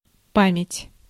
Ääntäminen
US UK : IPA : /ˈmɛməɹi/ US : IPA : /ˈmɛm(ə)ɹi/